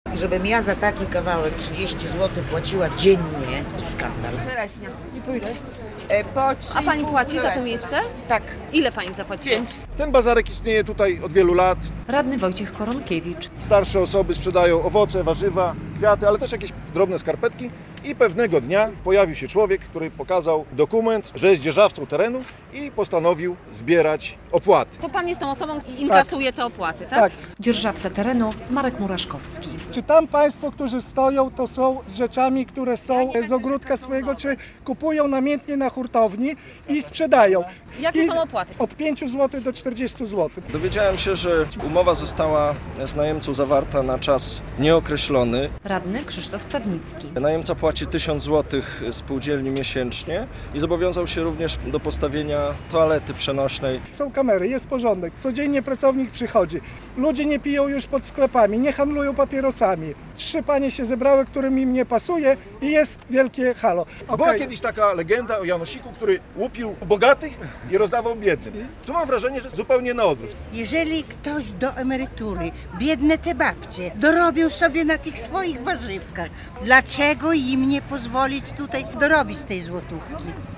Handel na osiedlowym bazarku z opłatami - relacja